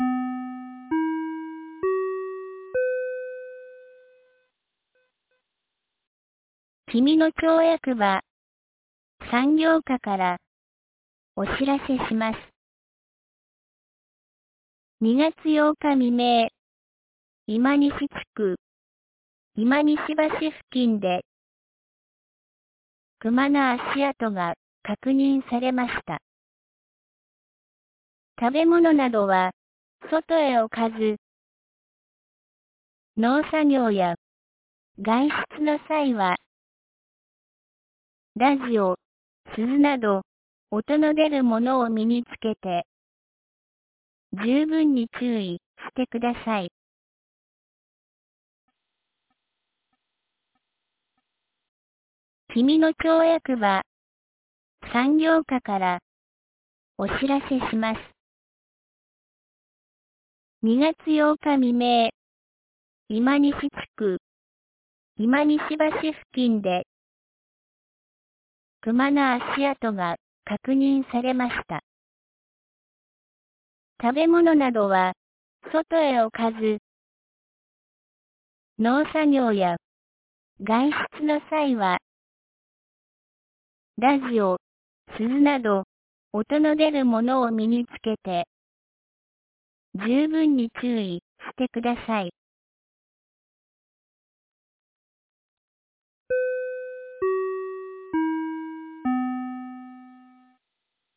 2026年02月10日 12時31分に、紀美野町より国吉地区へ放送がありました。